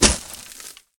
sand
sand2.ogg